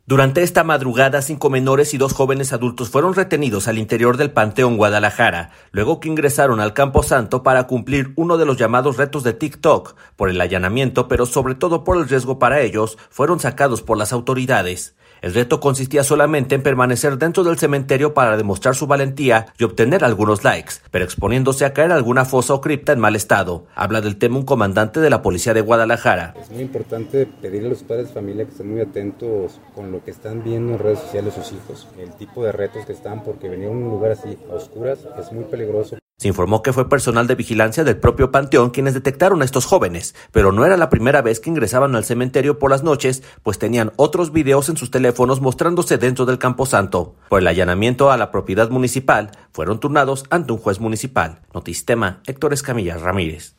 Habla del tema un comandante de la Policía de Guadalajara: